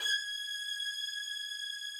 strings_080.wav